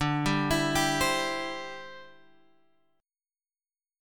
D 9th Suspended 4th